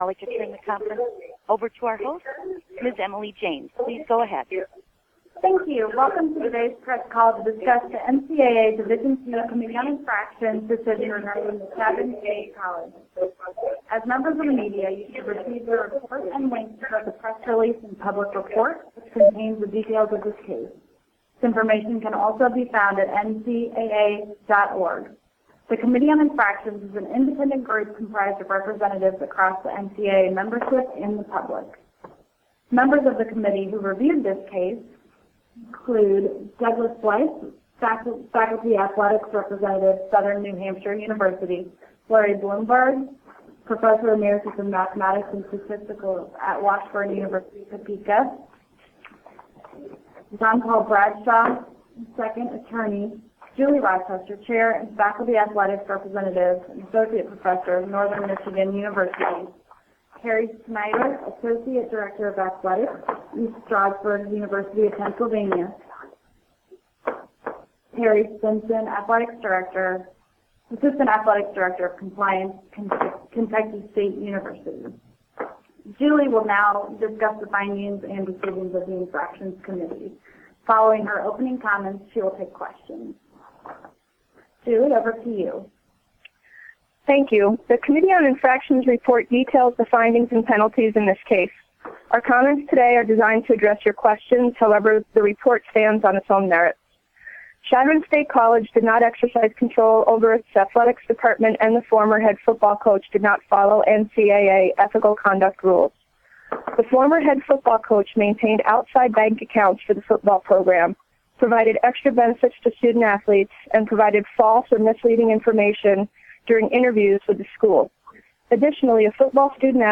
NCAA Division II Committee on Infractions Media Teleconference regarding Chadron State College